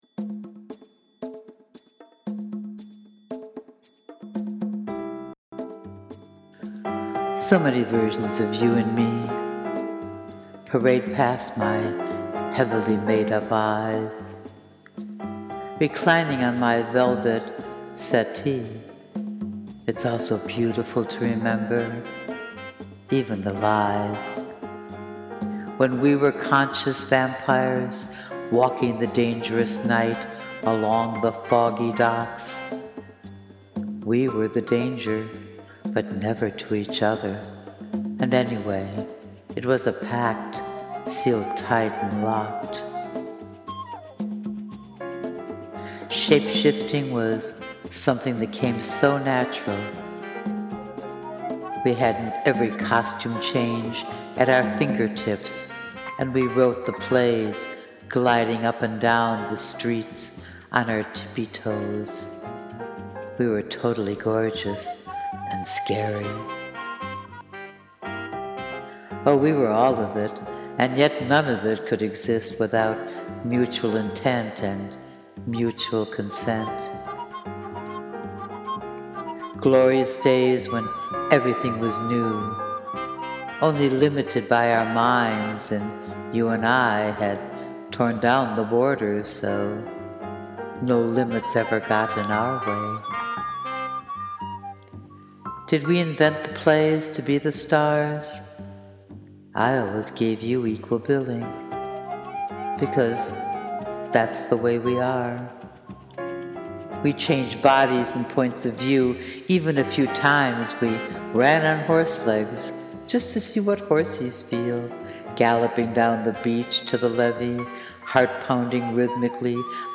The music is all mine:) Composed with Garage Band.